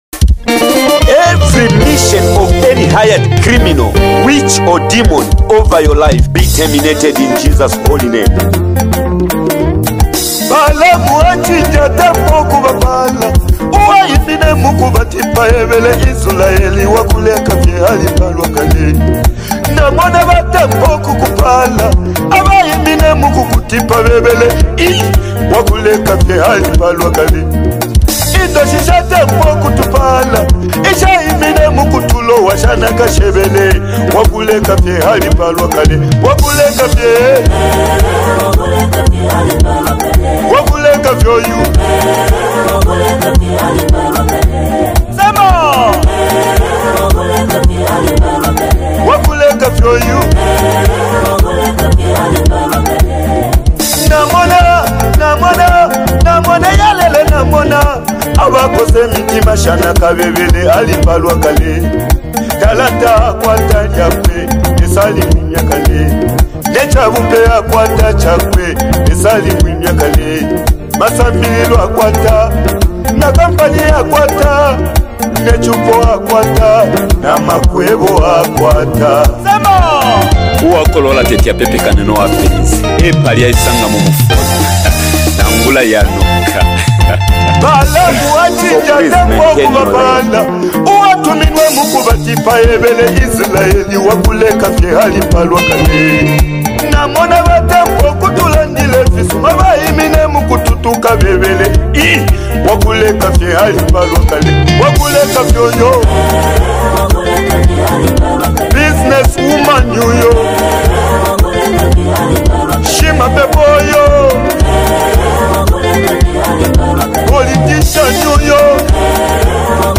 Latest Zambian Worship Song 2025
an anointed and uplifting worship song